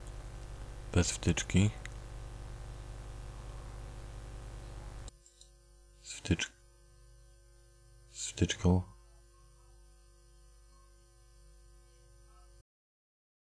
Znajduje się w niej wtyczka ReaFir która automatycznie wycisza przydźwięk.
TEST -brzmienie przedwzmacniacza mikrofonowego z zastosowaniem wtyczki ReaFir